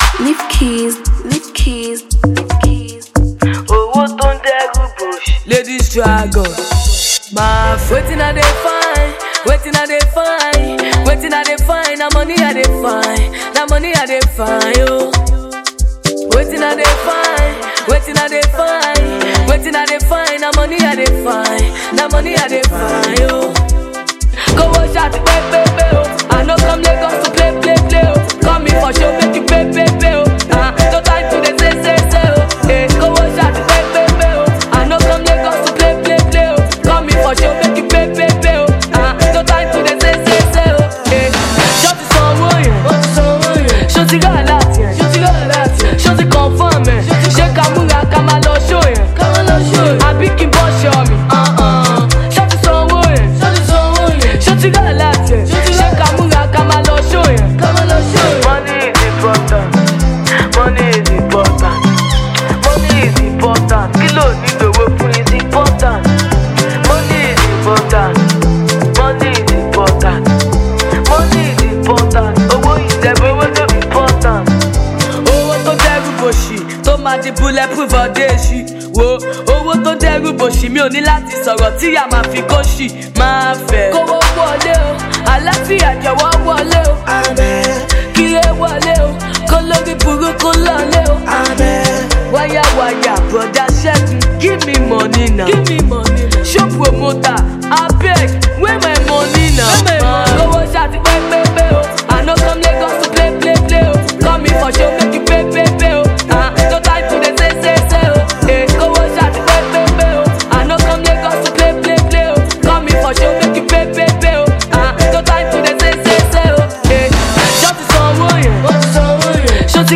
female rapper